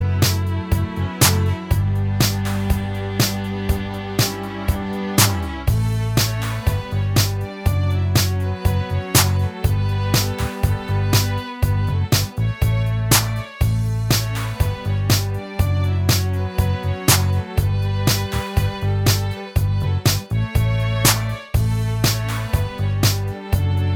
Minus Acoustic Guitars Pop (1980s) 3:36 Buy £1.50